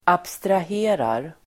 Ladda ner uttalet
Uttal: [abstrah'e:rar]